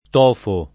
[ t 0 fo ]